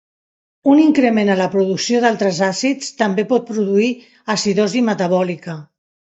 Ler máis (Inglés) increment, increase Frecuencia C2 Pronúnciase como (IPA) [iŋ.kɾəˈmen] Etimoloxía (Inglés) Tomado de latín incrēmentum In summary Borrowed from Latin incrēmentum.